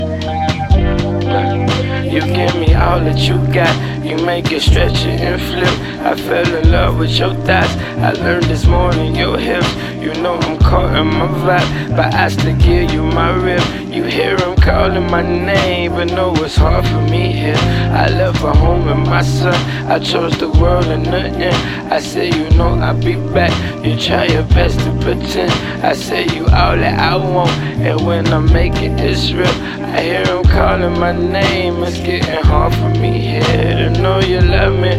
Жанр: Хип-Хоп / Рэп / Альтернатива